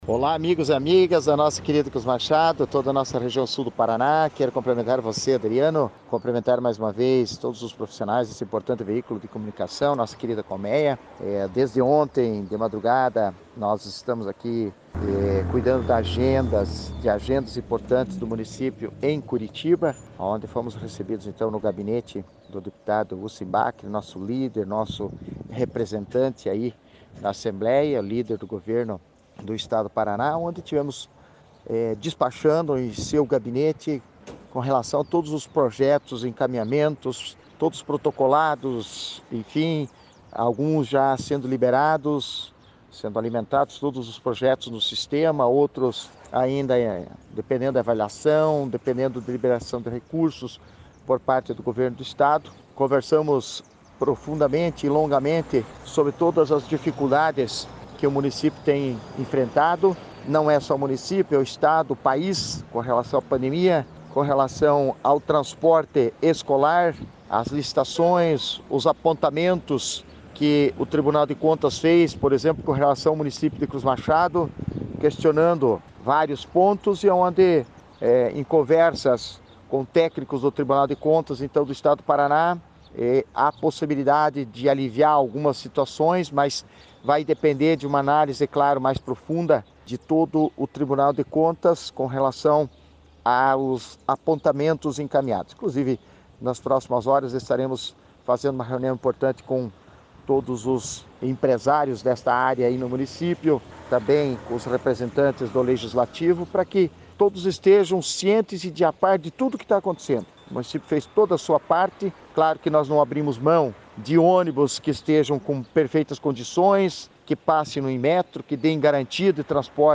Acompanhe a fala dos prefeitos de União da Vitória, Bachir Abbas; de General Carneiro, Joel Ferreira; de Cruz Machado, Antonio Szaykowski; e também da prefeita de Porto Vitória, Marisa Ilkiu, sobre a visita.
Prefeito Antonio Szaykowski: